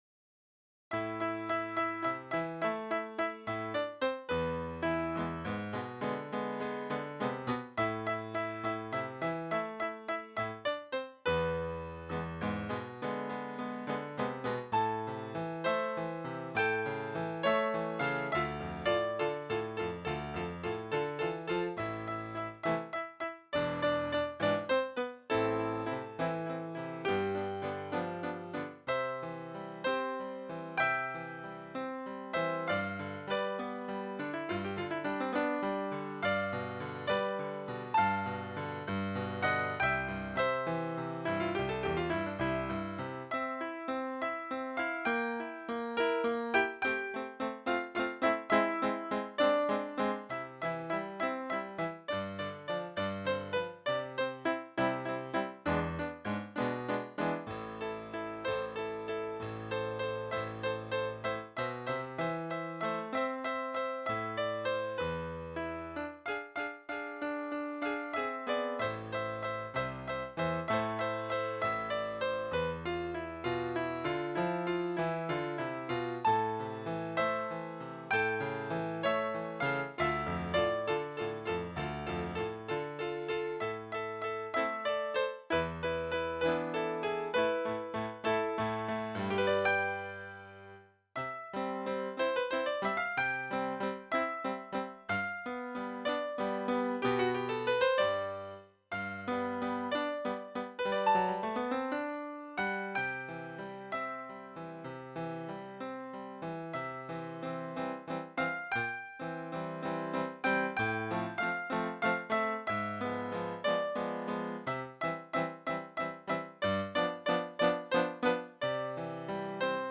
Partitura para piano / Piano score (pdf)
Escuchar partitura / Listen score (MP3) (Robot)